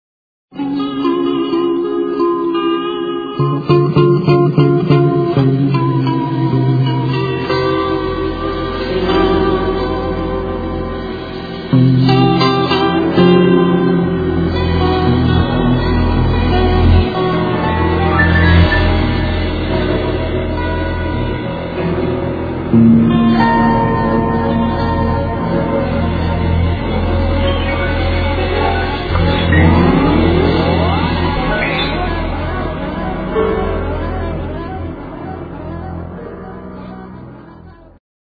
freejazz
tarogato
el. doublebass
drums
steel, el.guitar
piano). Live from Prague[2002].